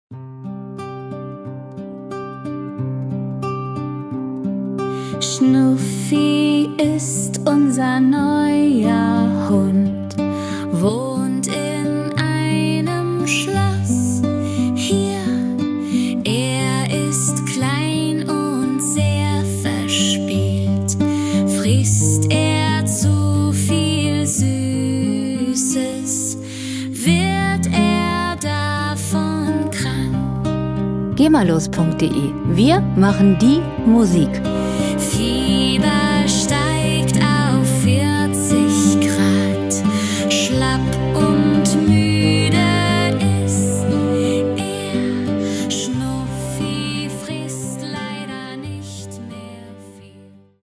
Gema-freie Kinderlieder
Musikstil: Kinderlied
Tempo: 89 bpm
Tonart: C-Moll
Charakter: traurig, lehrhaft
Instrumentierung: junge Sängerin, akustische Gitarre, Geigen